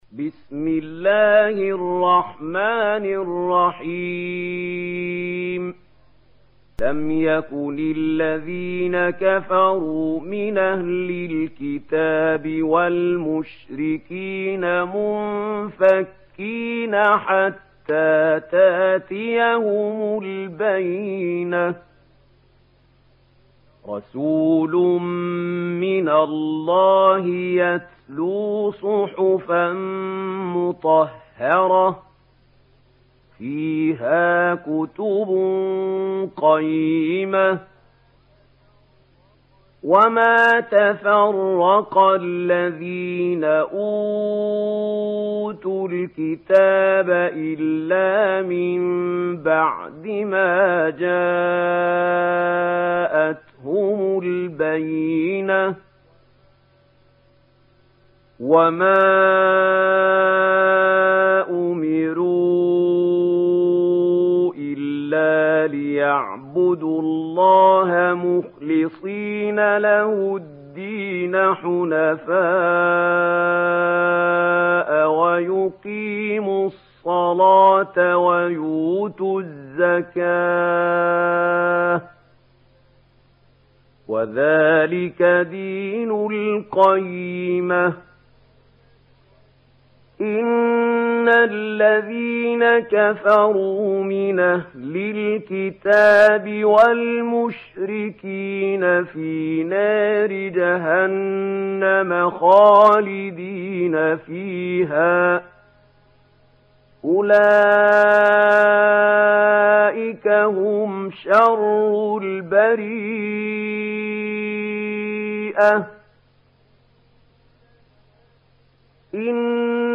دانلود سوره البينه mp3 محمود خليل الحصري (روایت ورش)